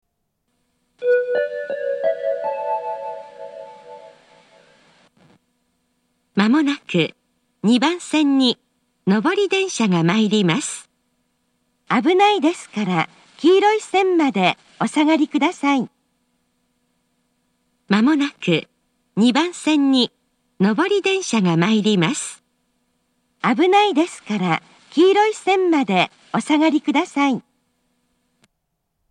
発車メロディーは「Water Crown」と「Gota del Vient」というありふれた組み合わせでしたが、音程が高く珍しいバージョンでした。
２番線接近放送
２番線発車メロディー 曲は「Water Crown」です。音程は高いです。